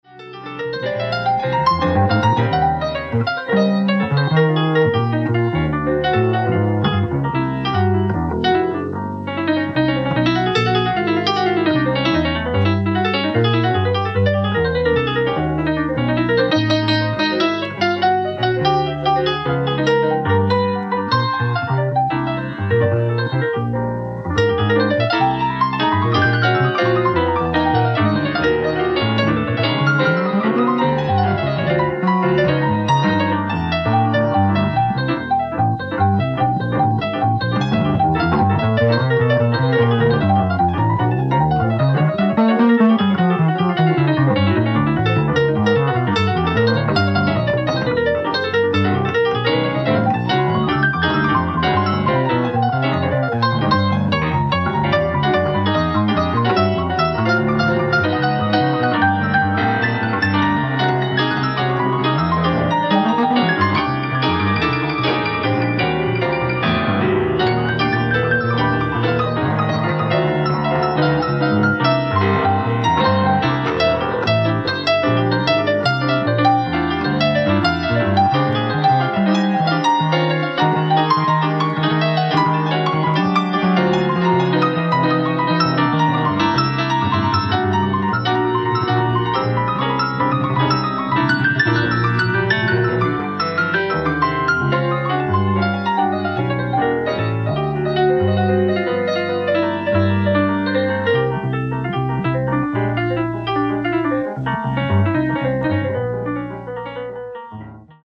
ライブ・アット・ポンペイ、イタリア 07/29/1983
※試聴用に実際より音質を落としています。